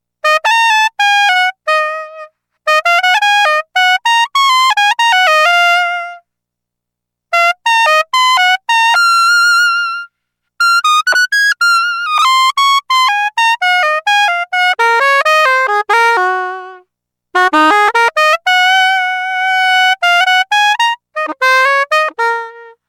Мелодии на звонок